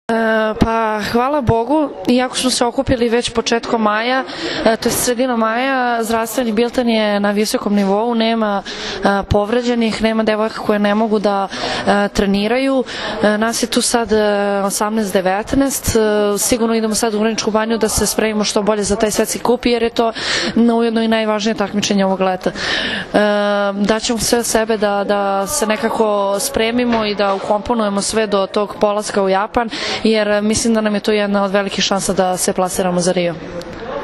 IZJAVA ANE BJELICE